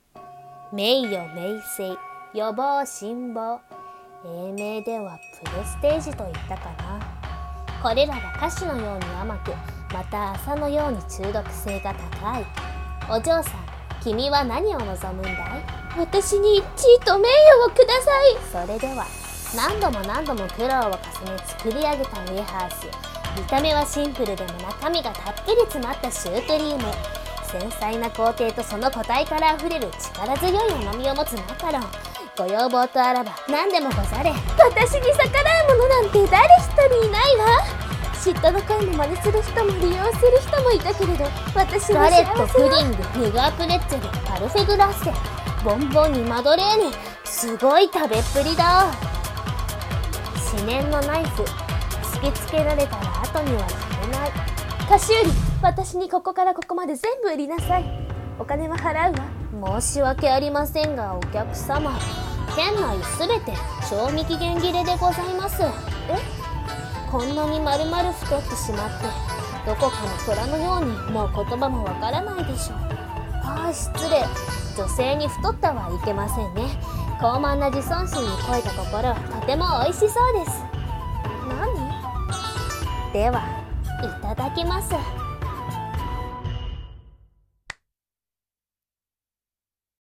CM風声劇「妖町の菓子売」